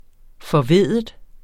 Udtale [ fʌˈveðˀəð ]